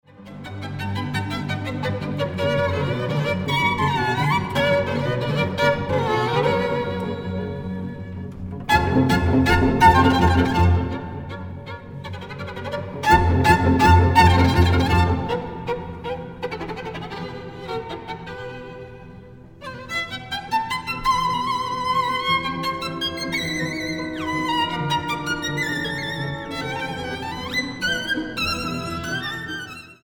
Genre: Classical
violin